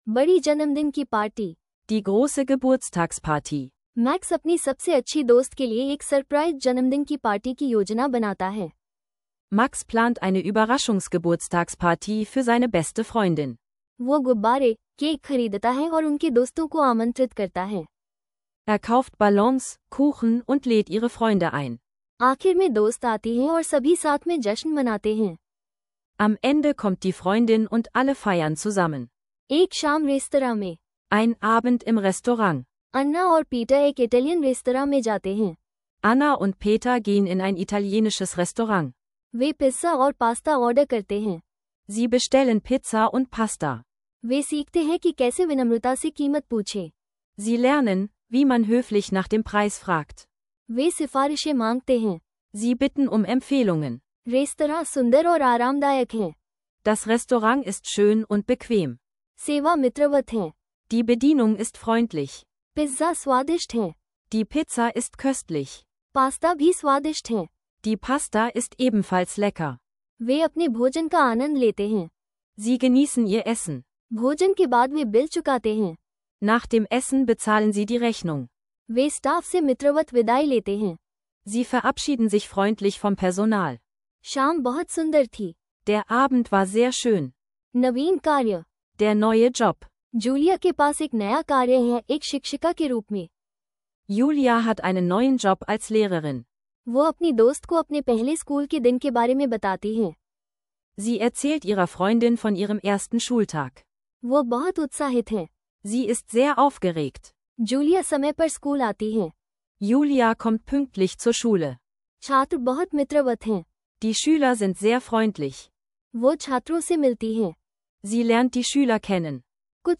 Lerne Hindi mit Alltagsdialogen über eine Geburtstagsparty und Restaurantbesuche – Ideal für Anfänger und Reisende!